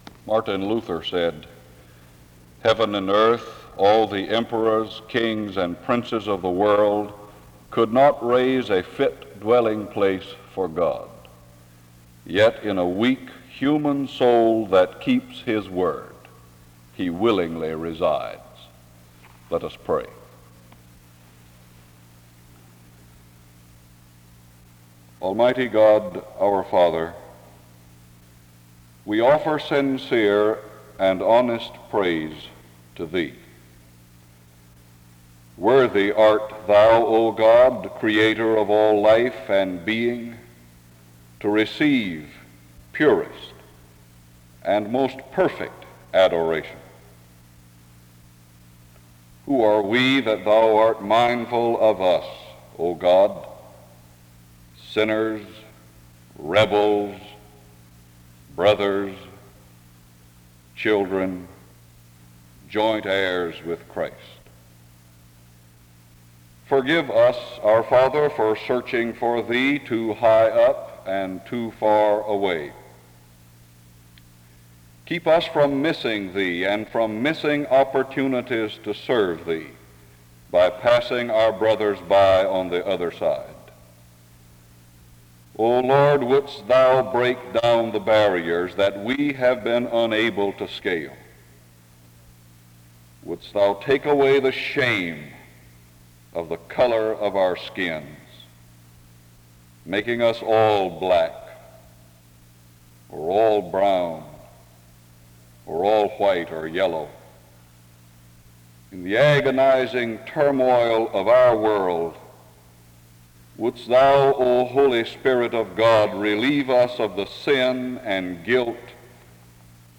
The service starts with prayer from 0:00-3:08. An introduction to the speaker is given from 3:24-4:29.